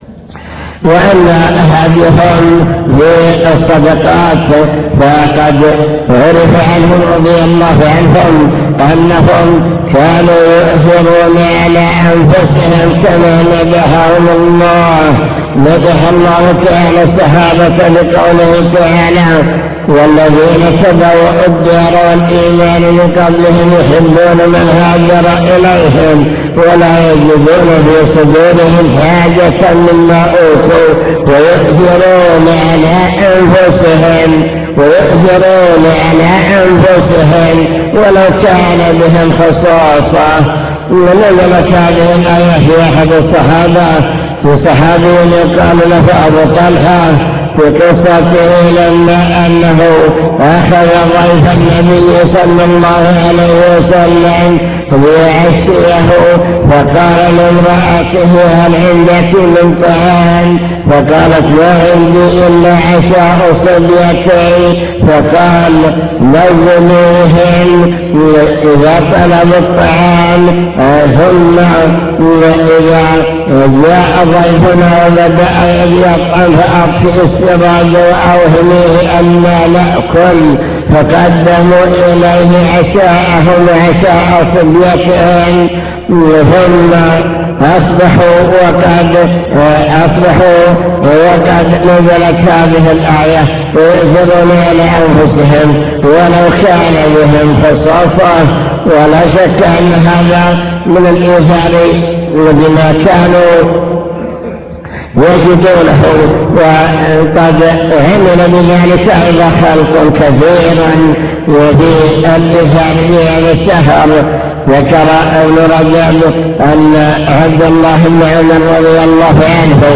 المكتبة الصوتية  تسجيلات - محاضرات ودروس  مجموعة محاضرات ودروس عن رمضان هدي السلف الصالح في رمضان